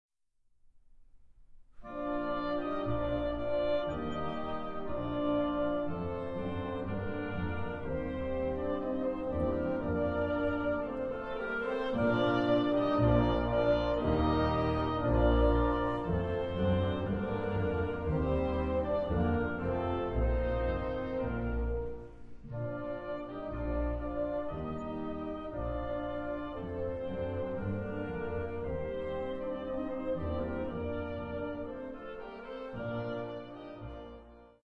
Chorale
Andante